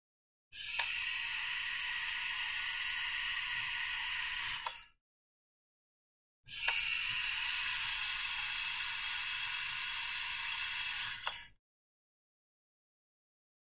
Oxygen Tank
Oxygen Tank; Repeated Long Oxygen Releases With Click, Close Perspective.